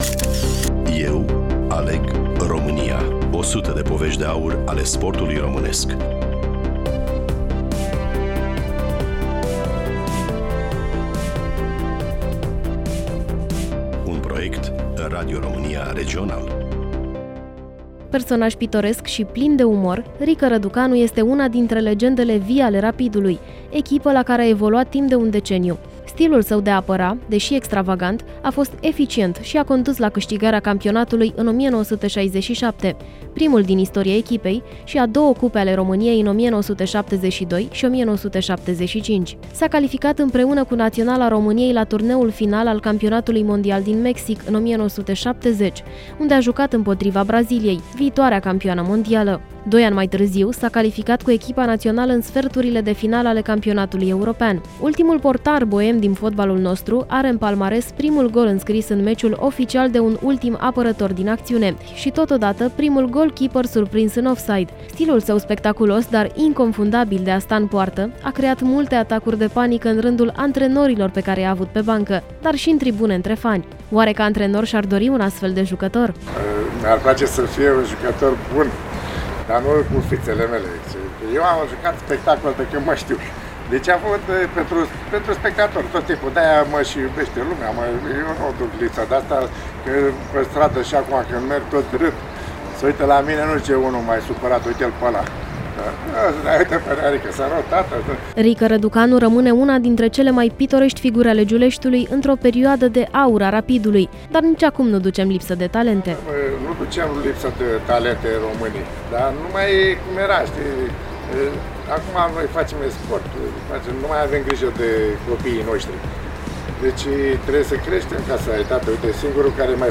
Prezentator
Voice over